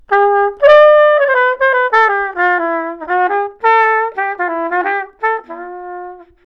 Podróżuje z małą trąbką, żeby nie tracić formy. Zaprezentował nam nawet próbkę brzmienia.